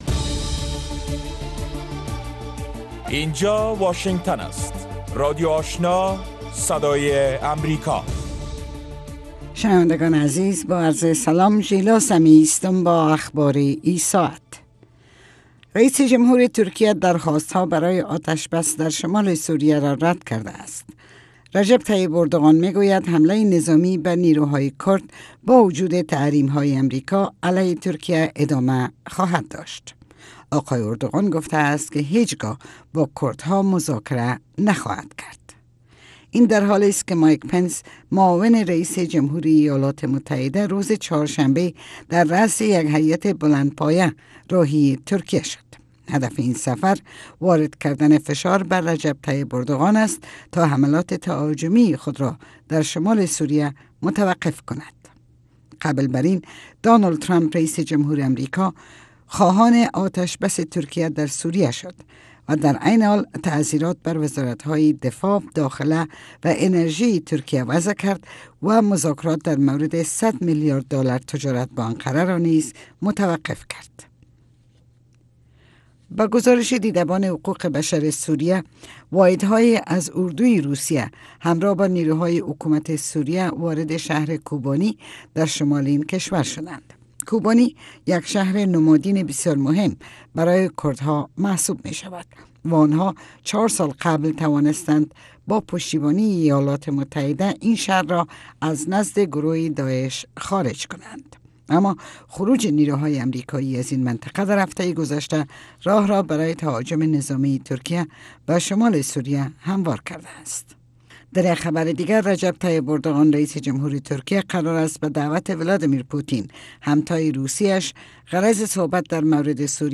نخستین برنامه خبری صبح